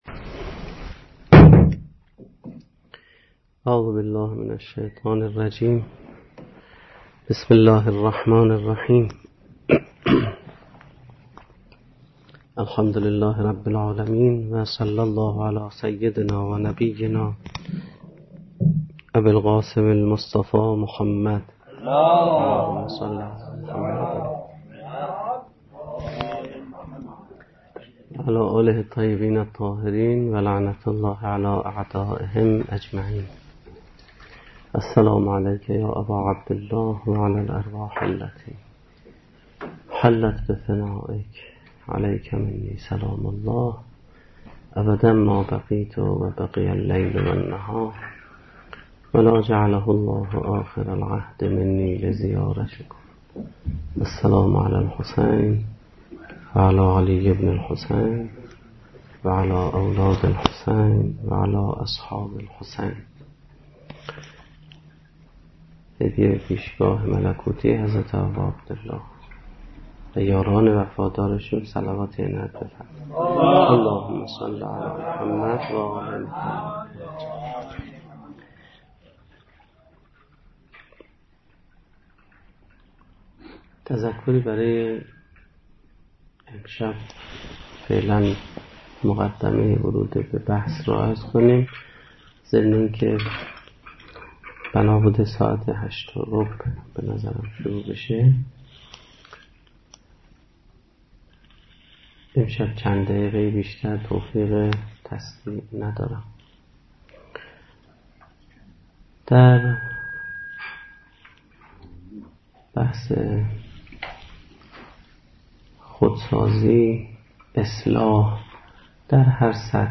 سخنرانی
ریشه های فرهنگی حادثه عاشورا - شب اول محرم الحرام 1436